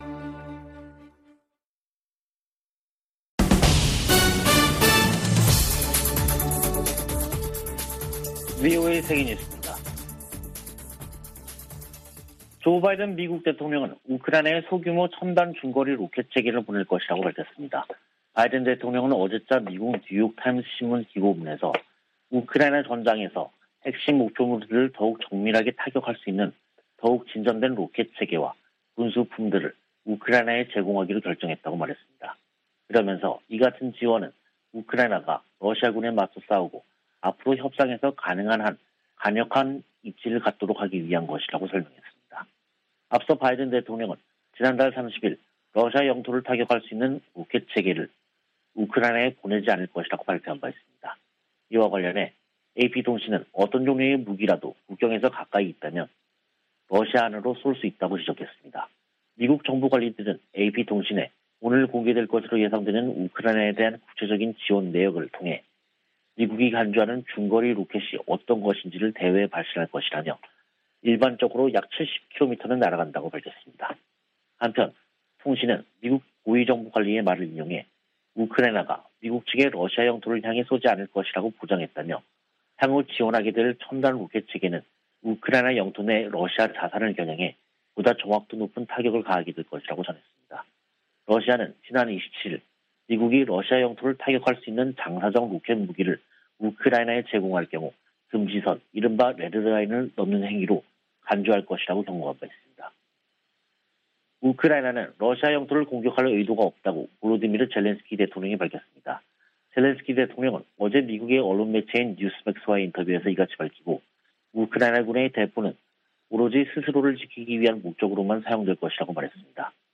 VOA 한국어 간판 뉴스 프로그램 '뉴스 투데이', 2022년 6월 1일 3부 방송입니다. 미국은 북한이 핵 실험을 실시한다면 유엔 안보리에서 추가 제재를 추진할 것이라고 유엔주재 미국 대사가 밝혔습니다. IPEF가 미국과 인도 태평양 지역 국가들 간 경제 관계를 더욱 강화할 것이라고 미 상무장관이 말했습니다. 미 중앙정보국(CIA)은 최근 갱신한 ‘국가별 현황보고서’에서 북한의 올해 ICBM 시험을 주목하고, 극심한 식량부족이 우려된다고 지적했습니다.